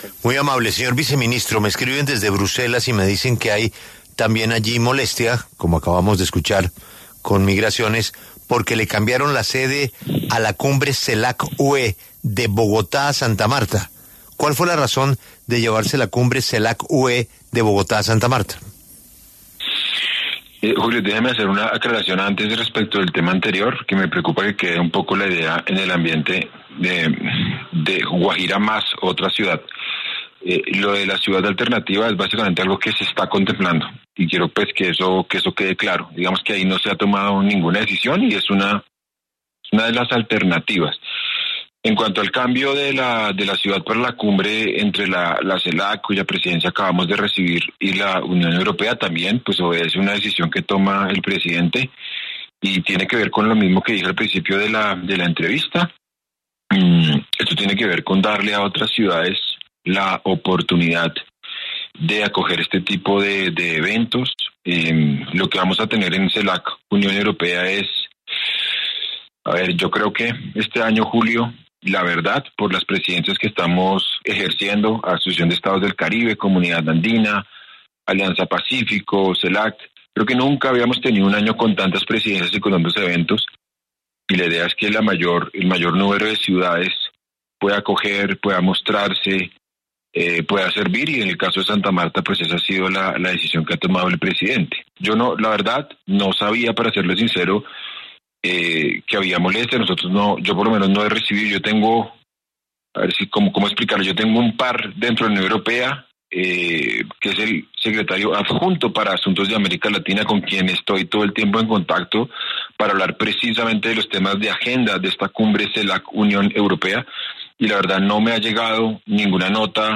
En conversación con La W, el vicecanciller de Asuntos Multilaterales, Mauricio Jaramillo, explicó por qué se dio el cambio de sede de la Cumbre Celas UE, pasando de Bogotá a Santa Marta.